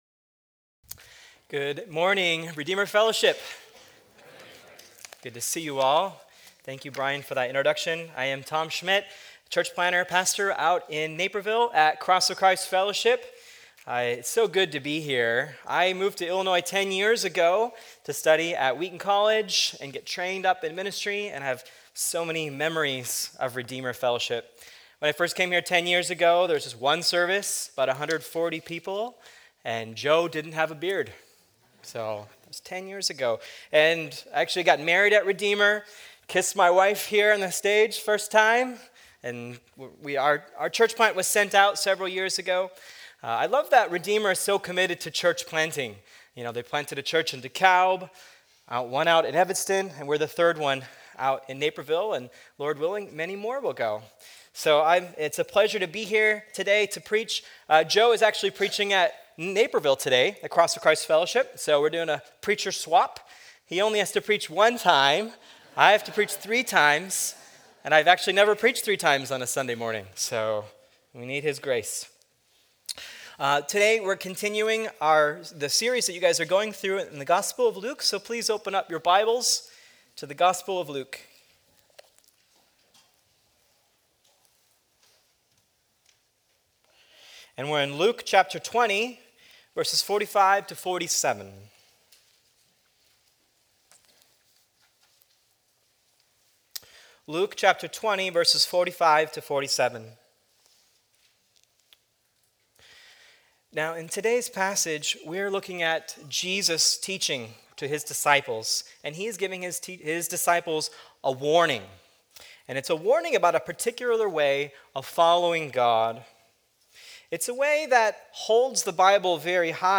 Sermon text: Luke 20:45–47 Download the discussion guide for this sermon here.